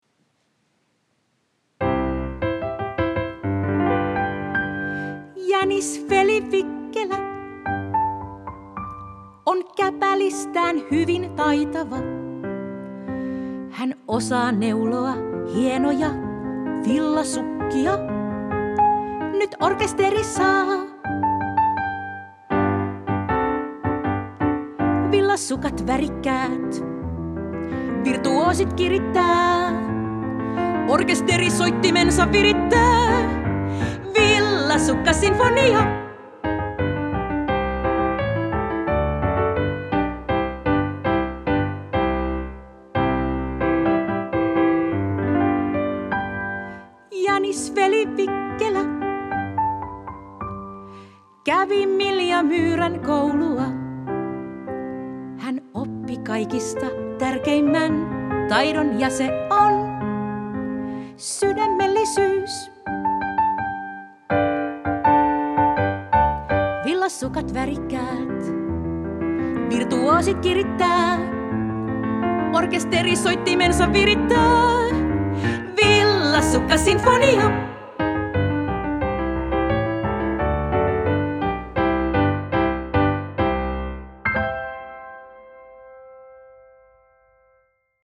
Äänikirjan upea tulkinta Kunniatohtori Eila Roine.